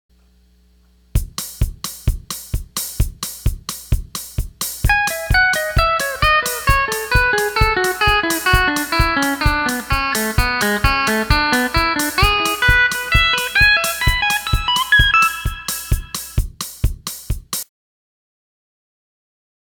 Fast No Echo Tab 1.wma